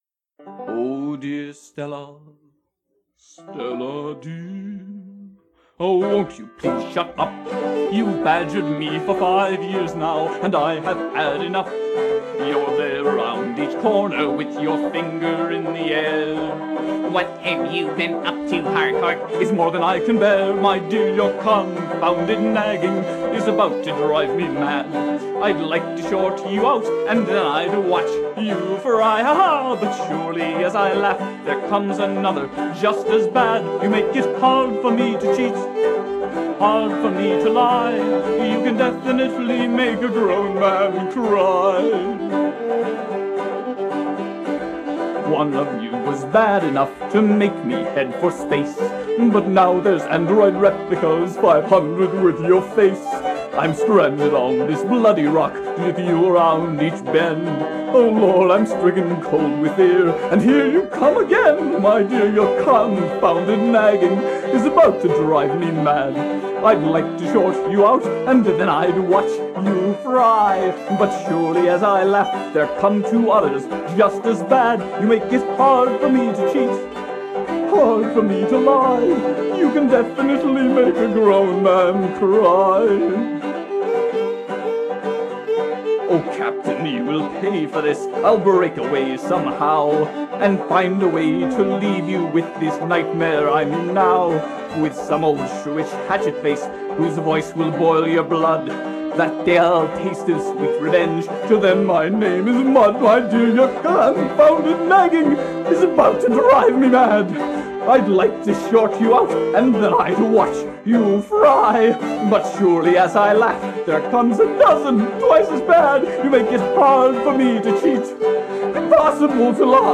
которая занималась публикацией музыки в жанре "филк"